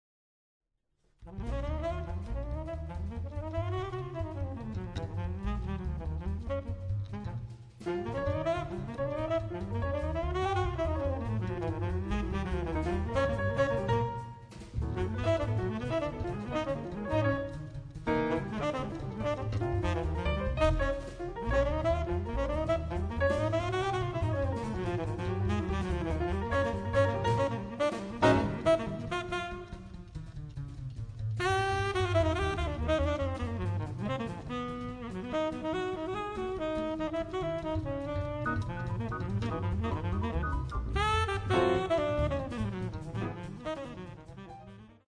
piano
sax
bass
drums
percussion